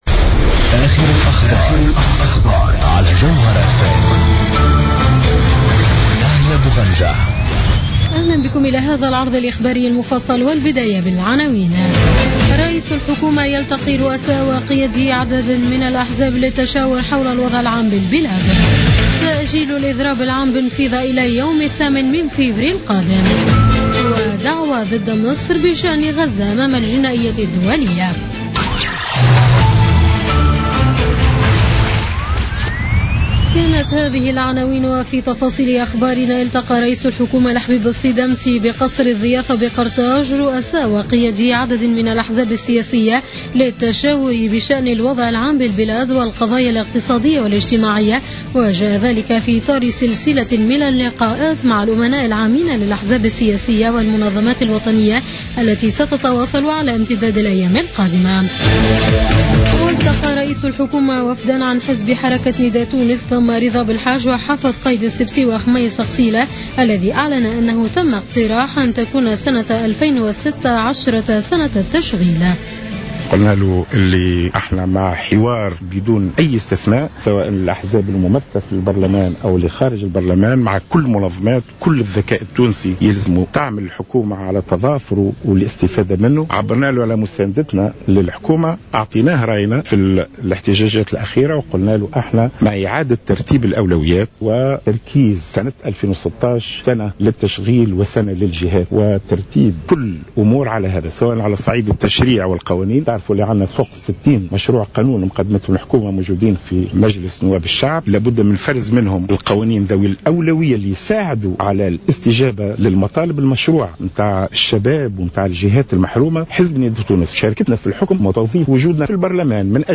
نشرة أخبار منتصف الليل ليوم الثلاثاء 26 جانفي 2016